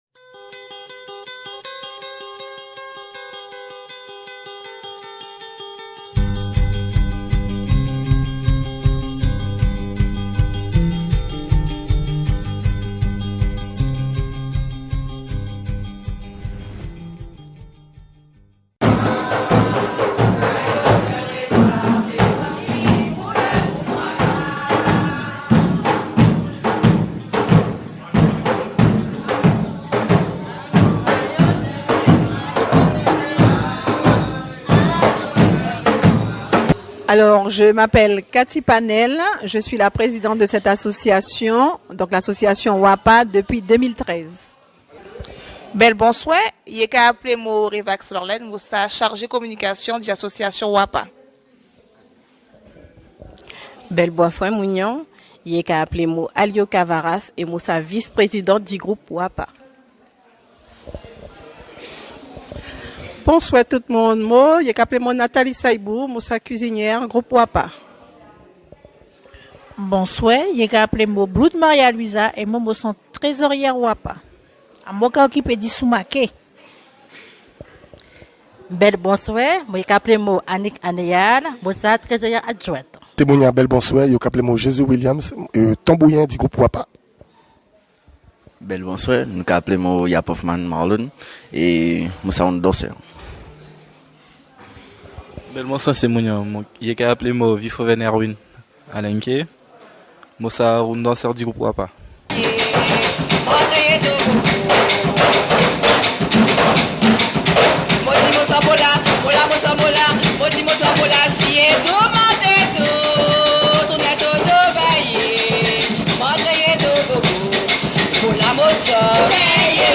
Reportage : Le groupe Wapa en déplacement à Paris!
Cette formation d'une cinquantaine de membres, mais que 18 d'entre eux avaient fait le déplacement fêtera l'année prochaine son quarantième anniversaire à proposé un programme riche sur fond de tambour et de danse traditionnelle à un public divers et varié.